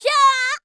shaman / voice / 1_heavy.wav
1_heavy.wav